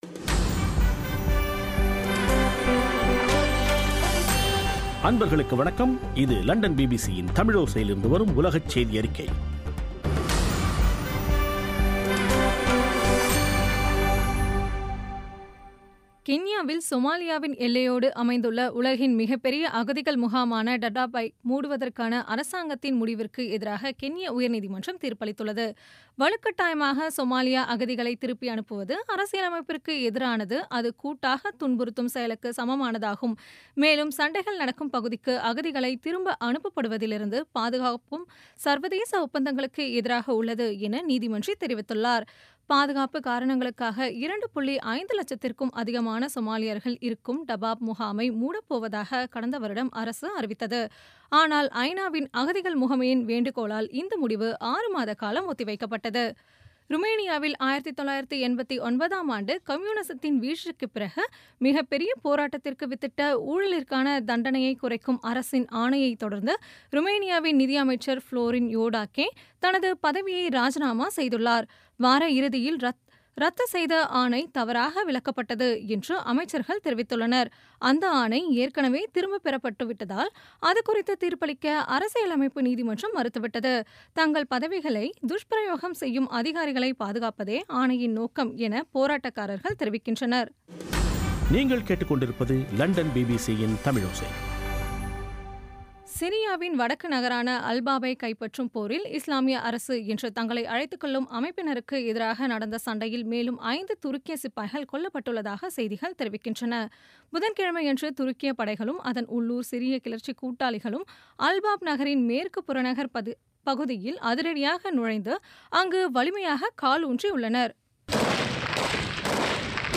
பிபிசி தமிழோசை செய்தியறிக்கை (09/02/2017)